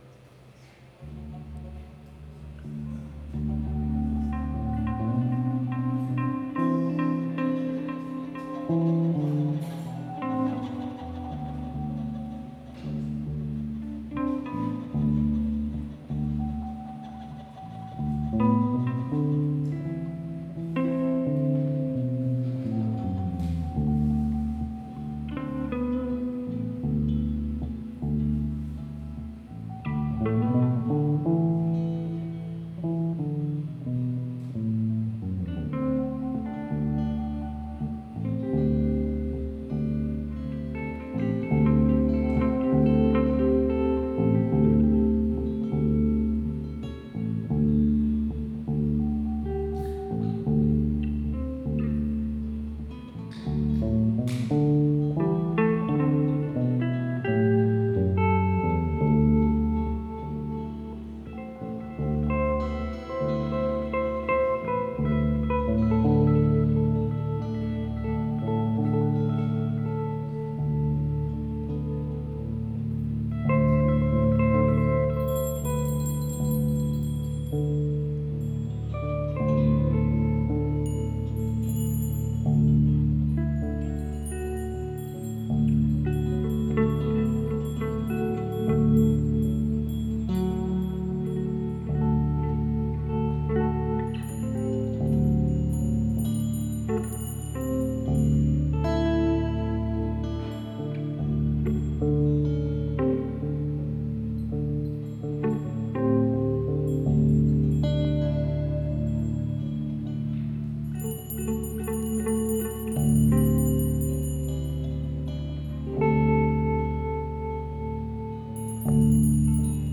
(Valatie, NY) August 20, 2016.